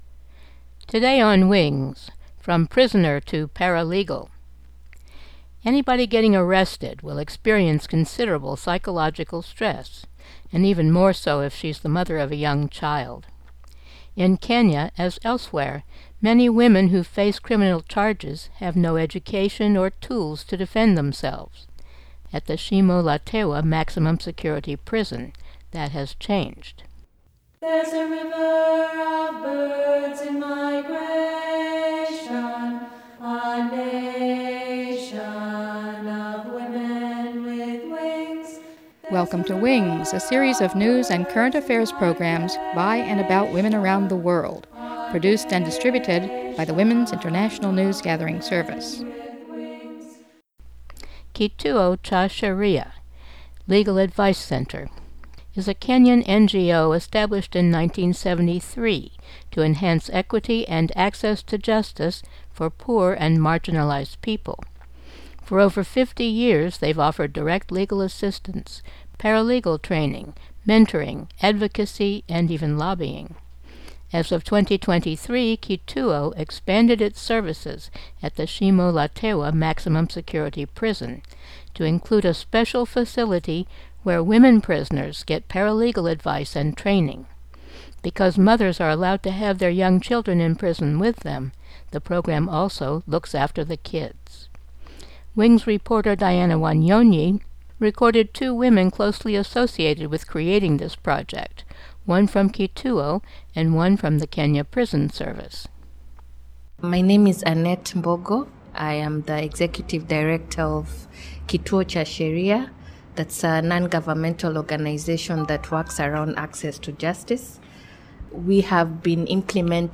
WINGS #27-25 Women in Prison Subtitle: train to defend their cases fairly in court Program Type: Weekly Program